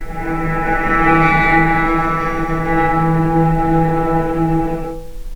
vc_sp-E3-pp.AIF